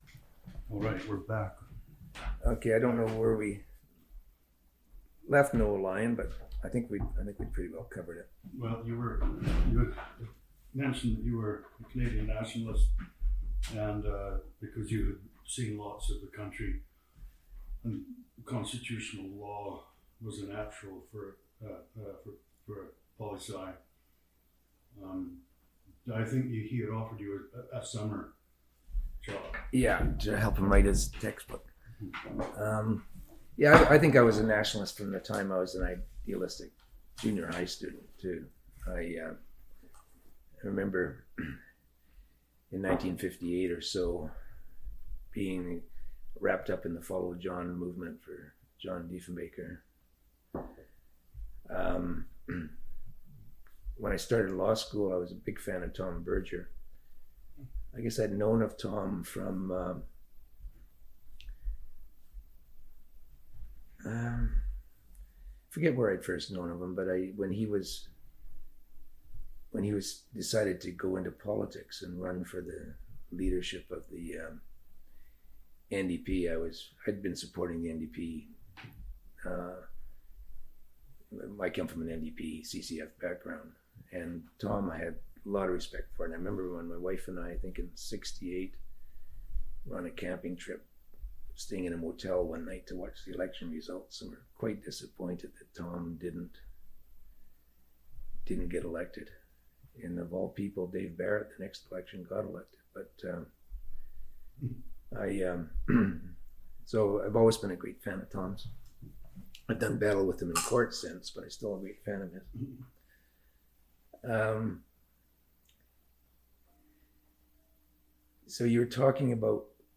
For more, listen to Allard Law History Project interview